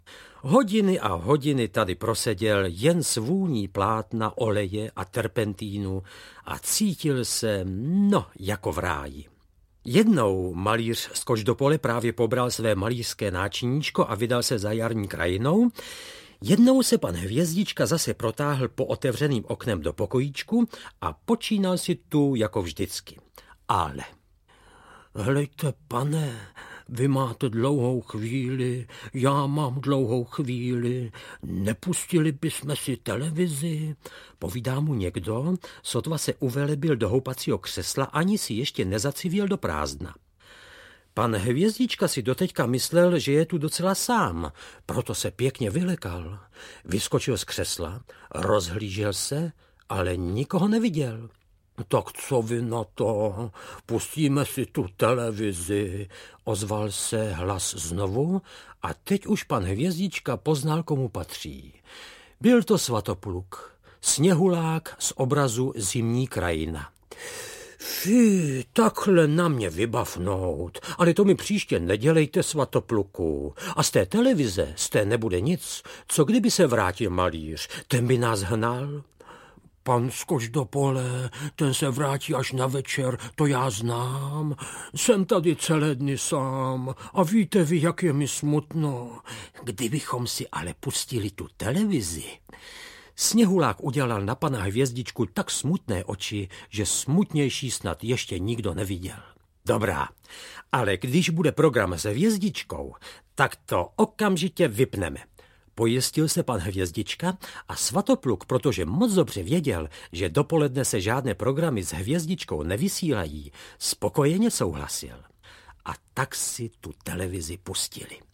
Audio kniha
Ukázka z knihy
• InterpretLubomír Lipský